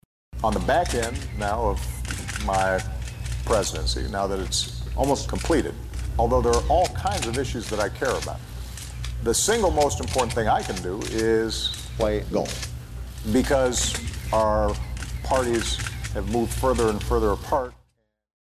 Obama-fake-news-golf.mp3